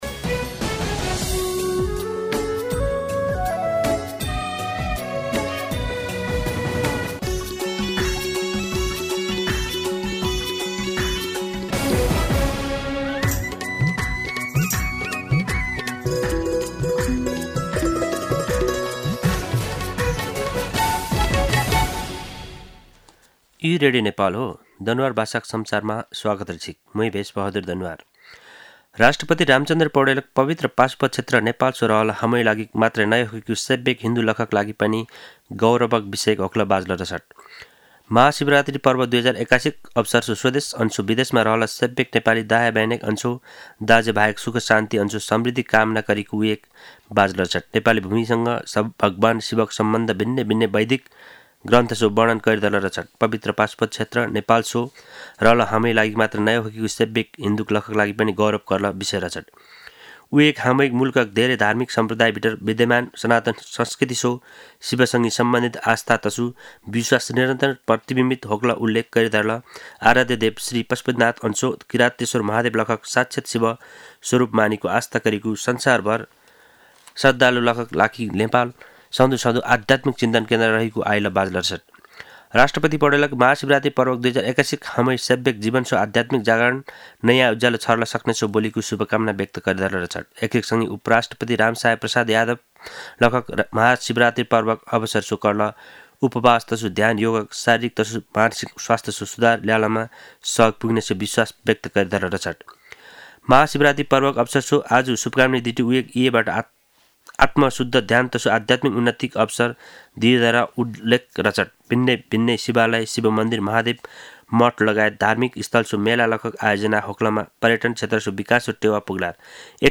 दनुवार भाषामा समाचार : १५ फागुन , २०८१